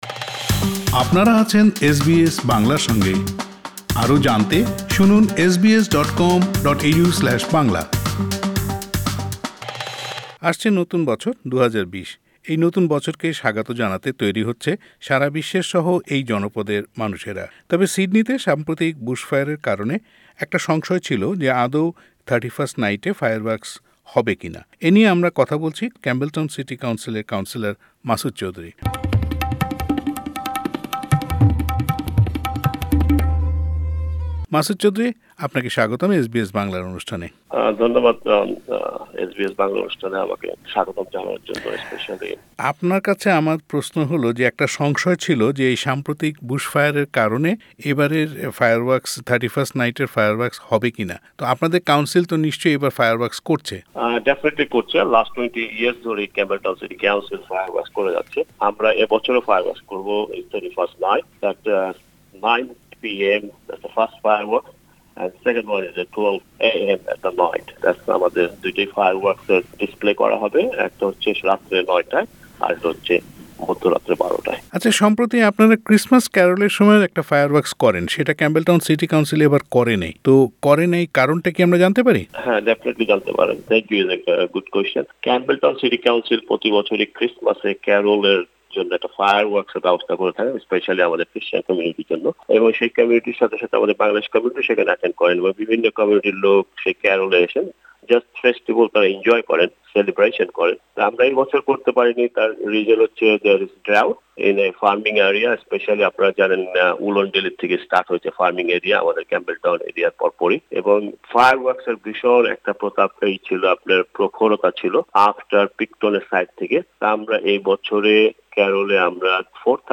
অন্যদিকে এই সিটি কাউন্সিলের বাঙালি কাউন্সিলর মাসুদ চৌধুরীর উদ্যোগে বুশ ফায়ার আক্রান্ত মানুষদের কাছে শুকনা খাবার তুলে দেয়ার জন্য মিন্টো মার্কেটে একটি সেন্টার খোলা হয়েছে। যেখানে কমিউনিটির বিভিন্ন স্তরের মানুষেরা দান করছে শুকনা খাবার আর সেগুলো পৌঁছে দেয়া হচ্ছে আক্রান্ত পরিবারগুলোর কাছে। এস বি এস বাংলার সঙ্গে এ নিয়ে কথা বলেছেন ক্যাম্পবেলটাউন সিটি কাউন্সিলের বাঙালি কাউন্সিলর মাসুদ চৌধুরী। কাউন্সিলর মাসুদ চৌধুরীর সাক্ষাৎকারটি শুনতে উপরের লিঙ্ক টিতে ক্লিক করুন।